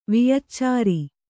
♪ viyaccāri